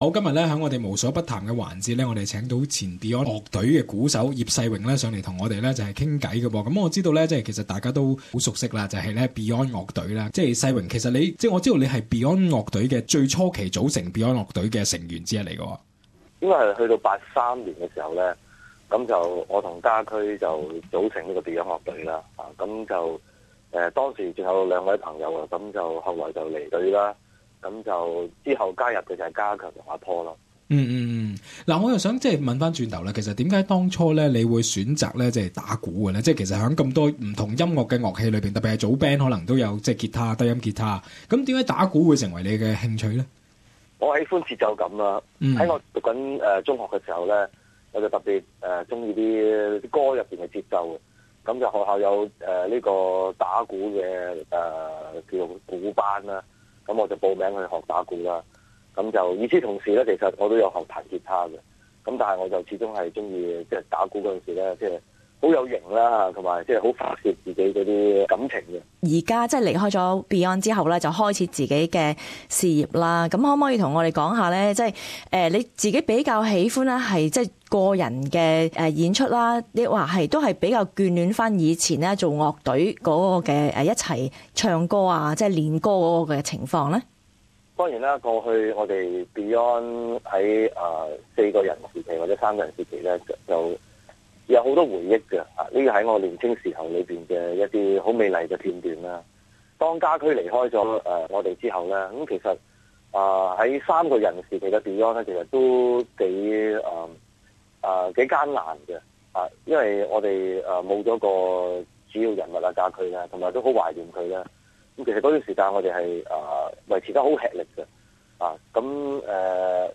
interview former member of a Hong Kong rock band , Beyond , Mr. Sai Wing Yip about his relationship with the other two remaining members and his tour in Australia in April.